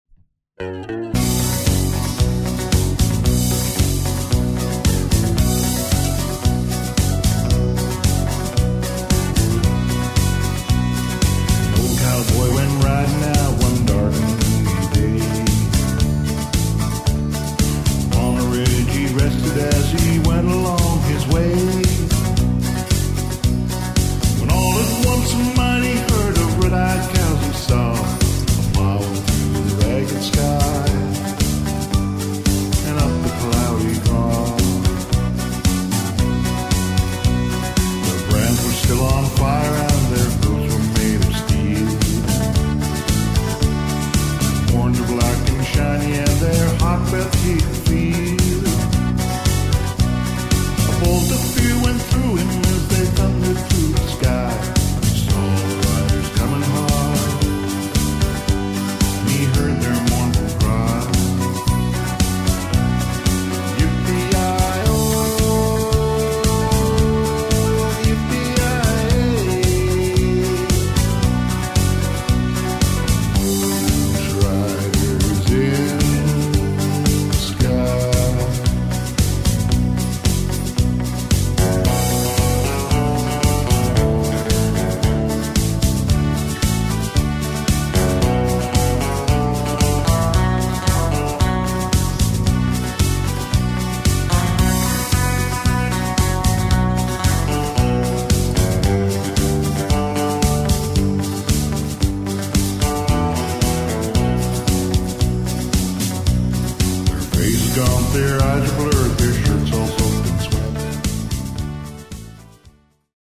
Quicksteps / Jives